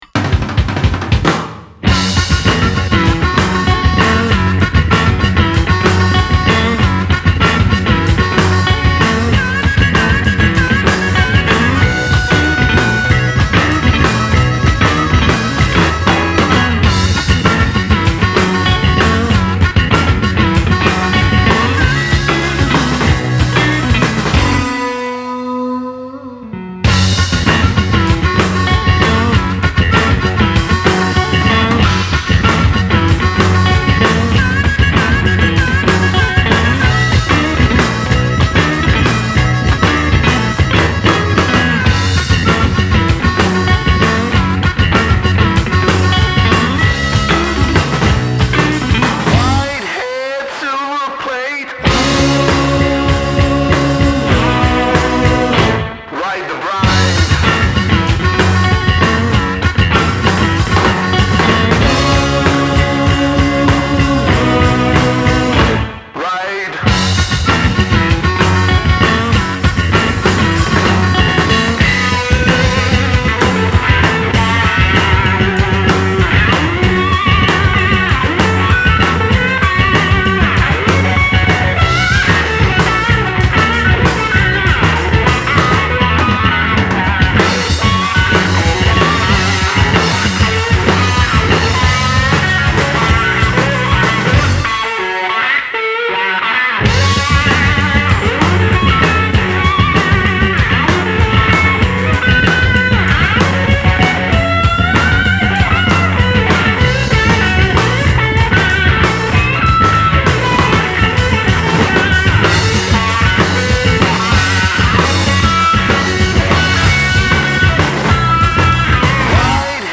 bassista
chitarra
power-trio
batterista